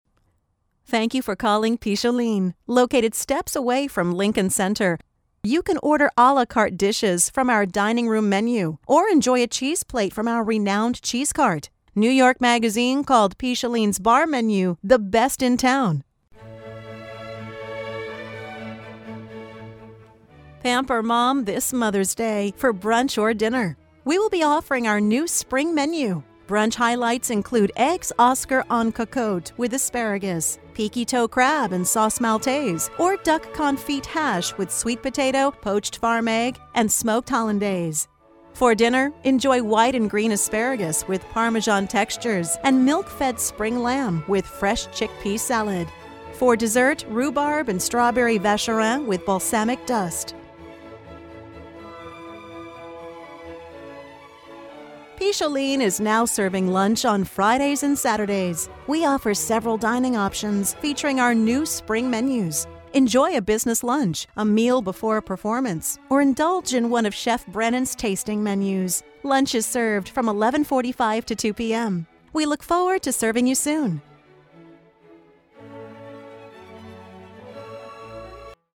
Samples of Informer Menu Messages on hold
Picholine: Greeting and Marketing Message on hold